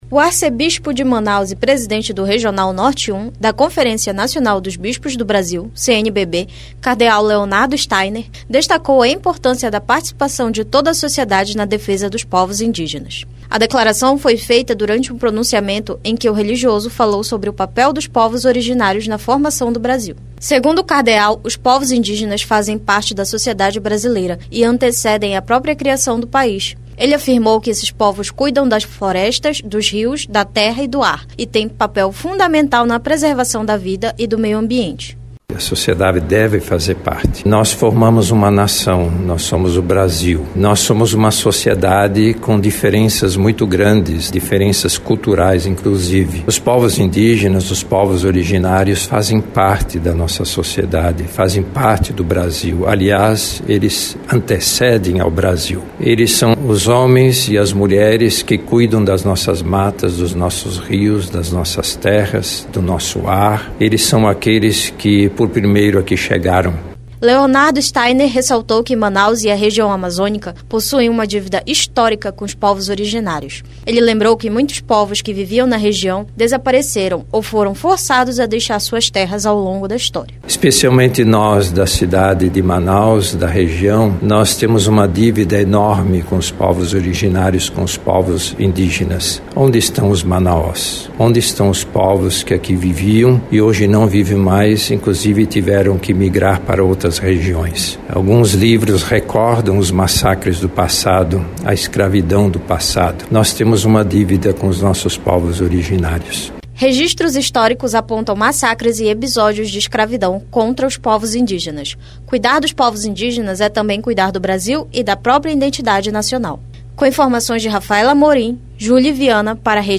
O arcebispo de Manaus e presidente do Regional Norte 1 da CNBB, Cardeal Leonardo Steiner, reforçou a importância da participação de toda a sociedade na defesa dos povos indígenas. Em pronunciamento, o religioso lembrou que os povos originários antecedem a criação do Brasil e têm papel fundamental na preservação das florestas, rios, terras e do…